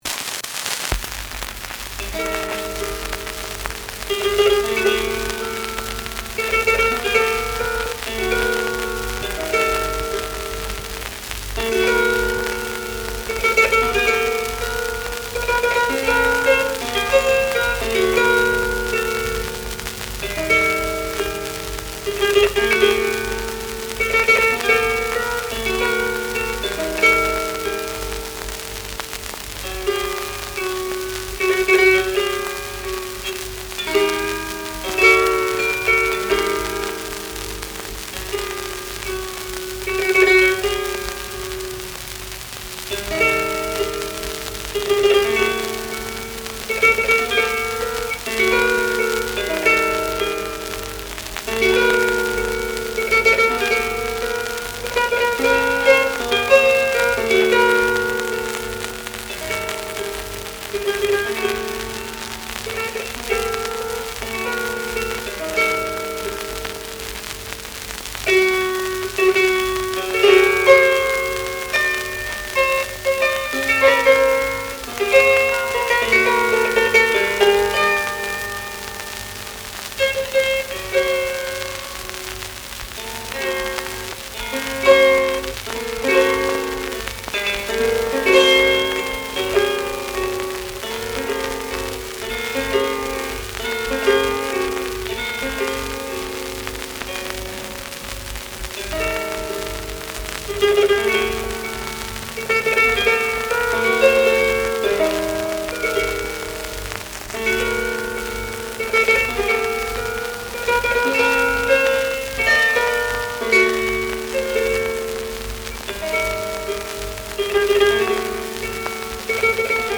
I have transferred them using a hifi turntable and lightweight pickup.
on the Bardic Harp